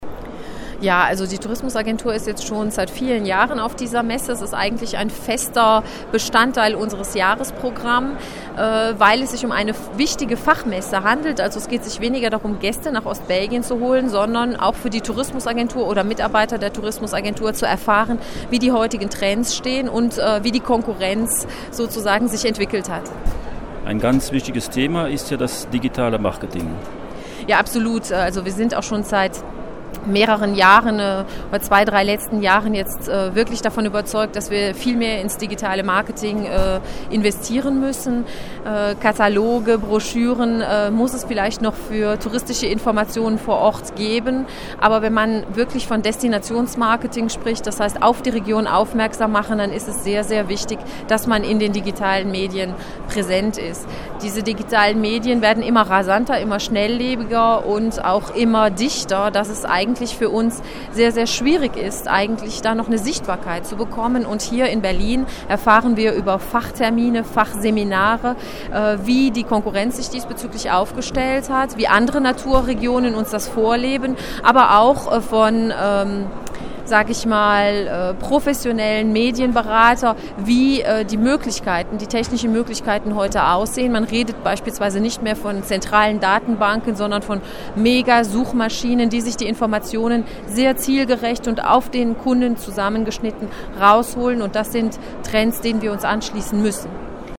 befindet sich zur Zeit bei der weltgrößten Reisemesse, der ITB (Internationale Tourismus-Börse) in Berlin.